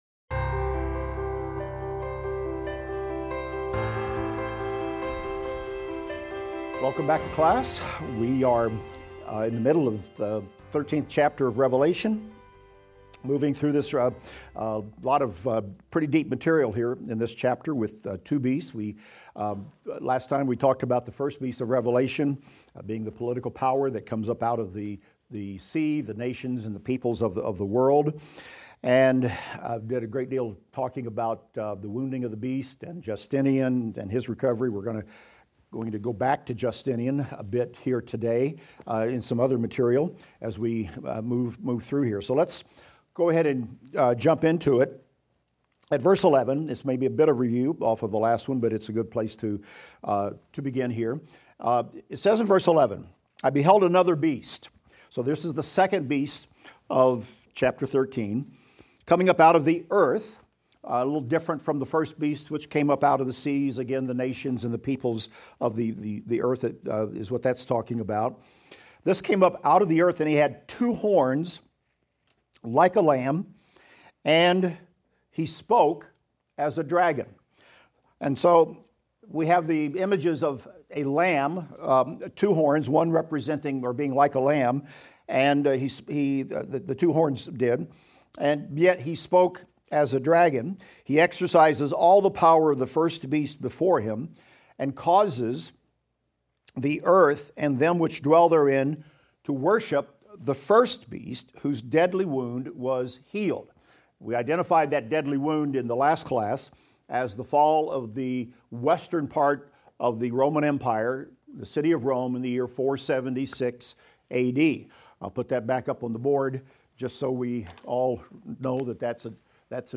Revelation - Lecture 43 - audio.mp3